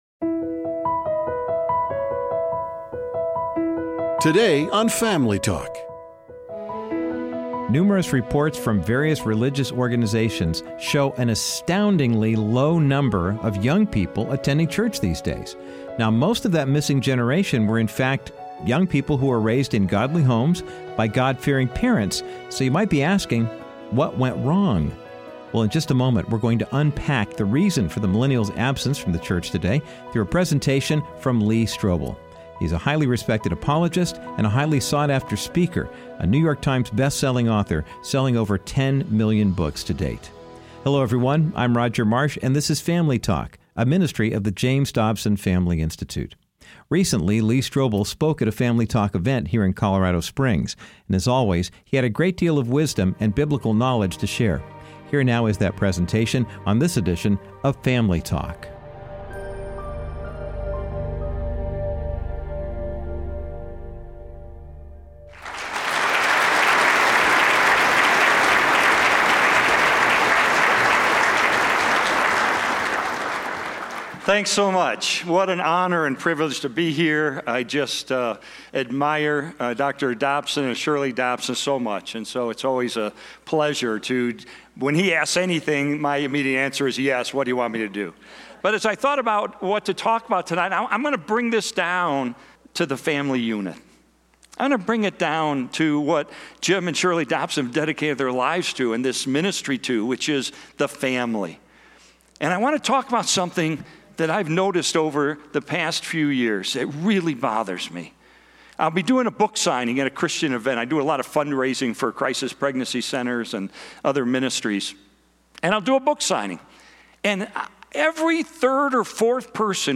Host Dr. James Dobson
Guest(s):Lee Strobel